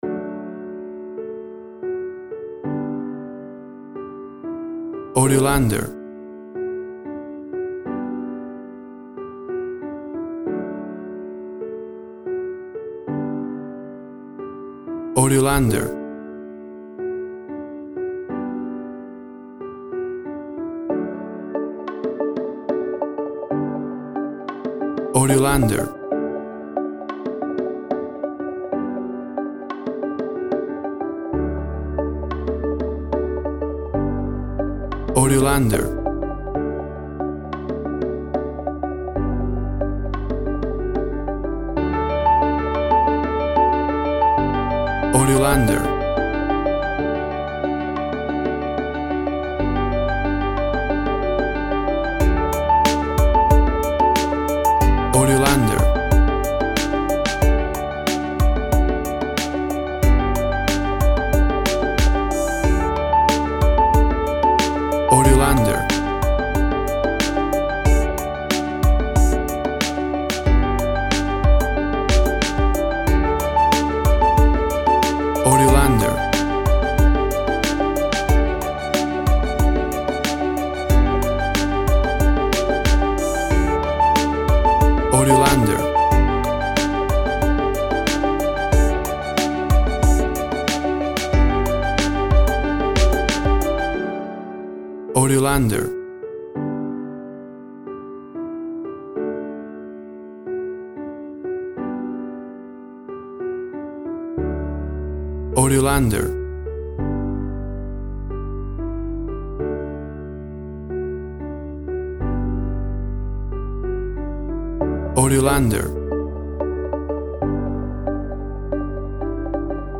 WAV Sample Rate 16-Bit Stereo, 44.1 kHz
Tempo (BPM) 92